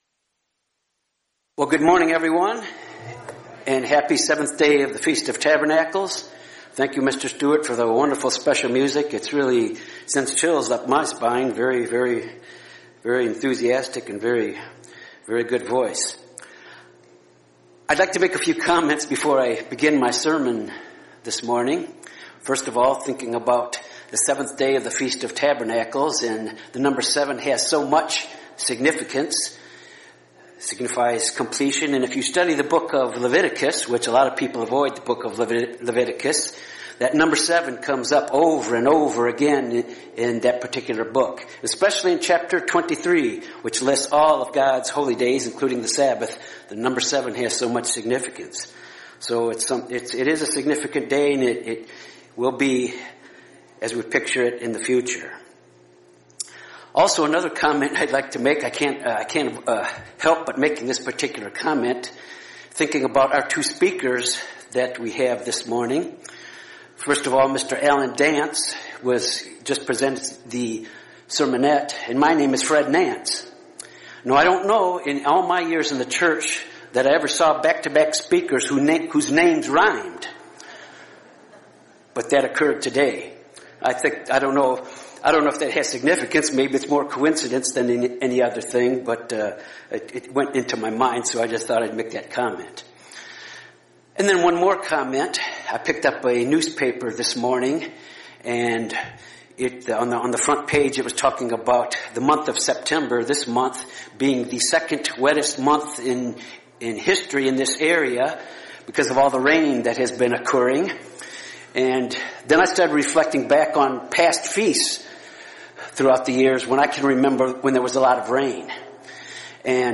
This sermon was given at the Galveston, Texas 2018 Feast site.